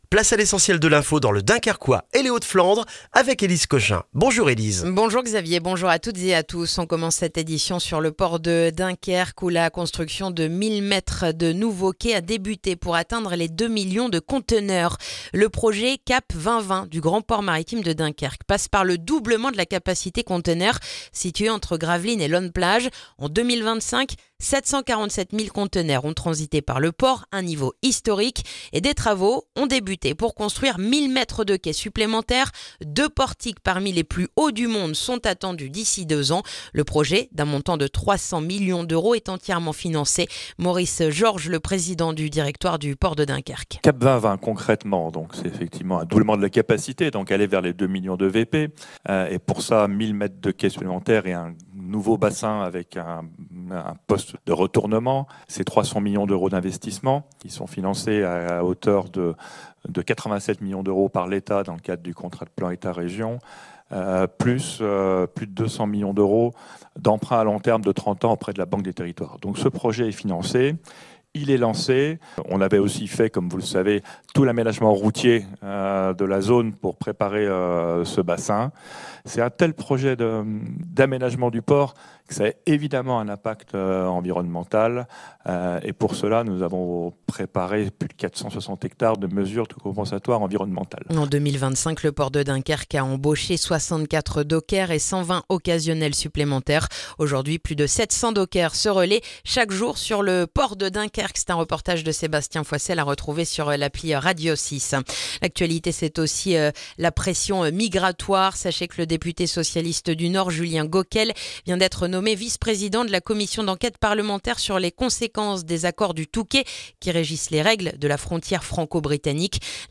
Le journal du mercredi 28 janvier dans le dunkerquois